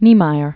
(nēmīər), Oscar 1907-2012.